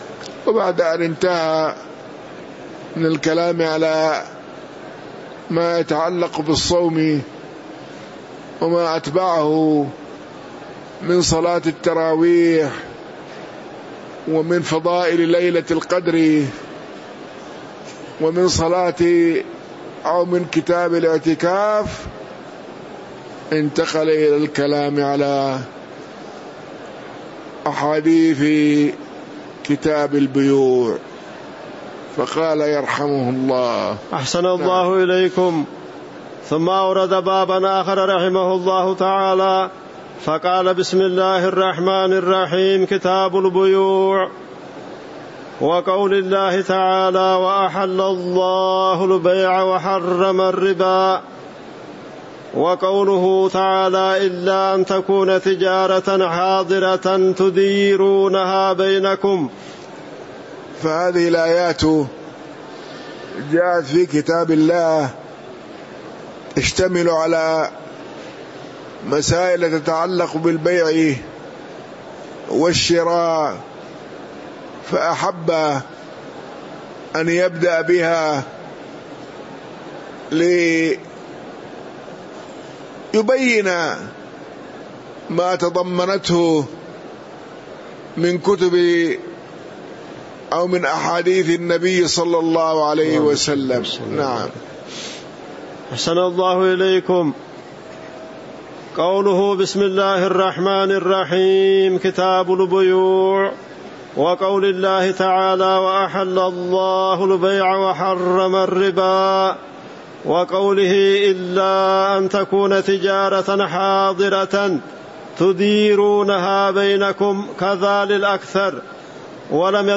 تاريخ النشر ٢٥ صفر ١٤٤٥ هـ المكان: المسجد النبوي الشيخ